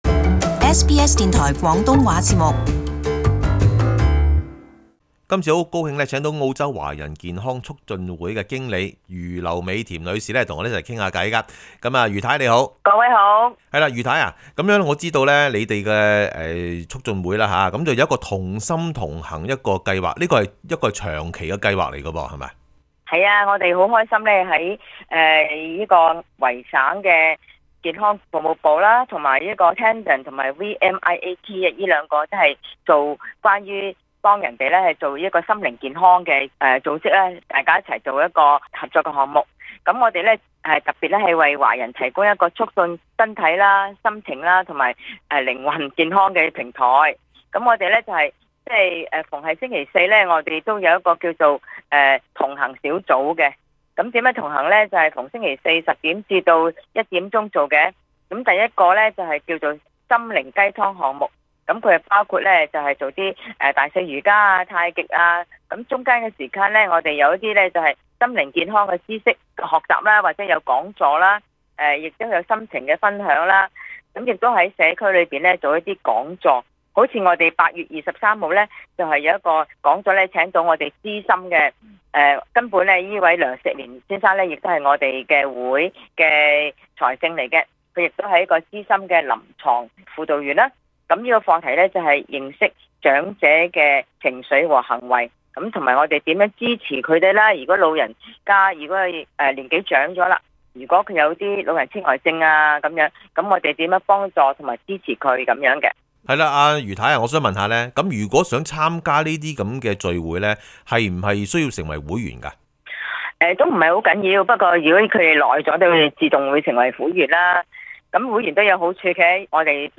访问澳洲华人健康促进会同心同行八月份活动